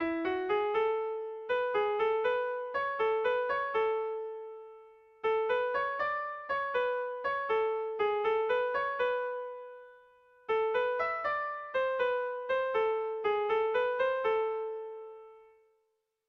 Air de bertsos - Voir fiche   Pour savoir plus sur cette section
Seiko berezia, 3 puntuz (hg) / Hiru puntuko berezia (ip)
ABD